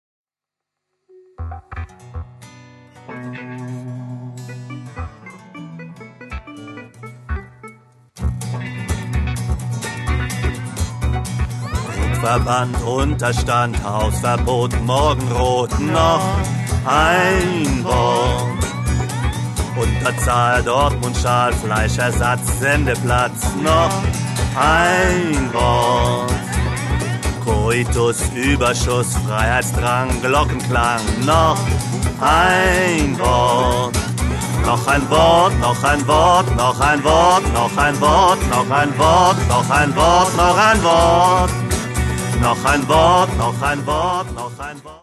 Ich mache deutschsprachige Lieder zur Gitarre.